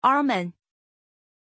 Pronunciation of Arman
pronunciation-arman-zh.mp3